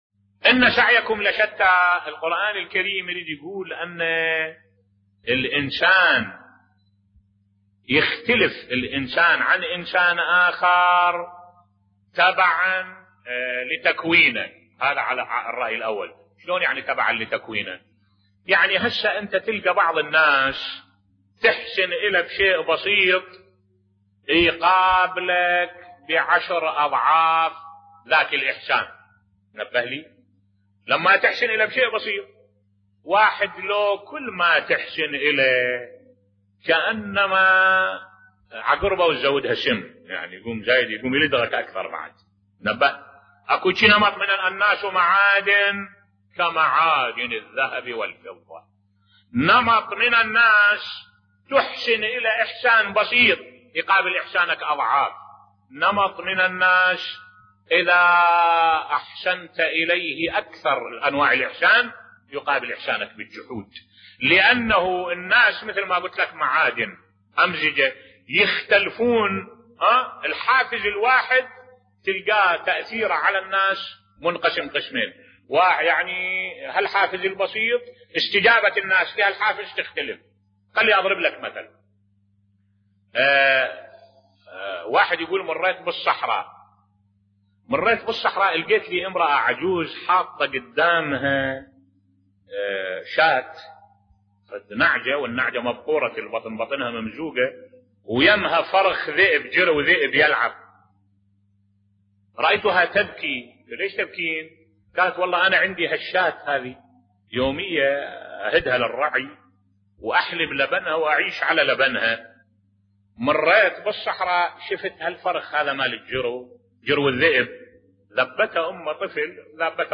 ملف صوتی لا يوجد في التاريخ أقل وفاء من العباسيين بصوت الشيخ الدكتور أحمد الوائلي